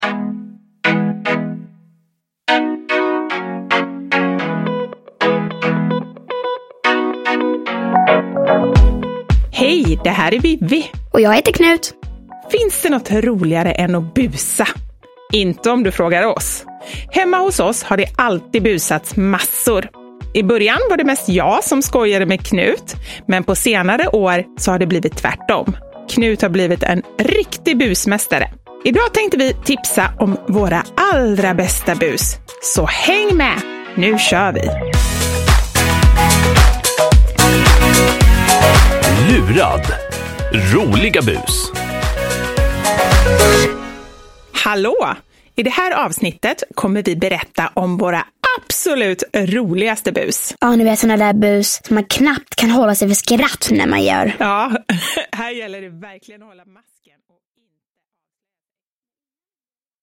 Lurad! Roliga bus – Ljudbok – Laddas ner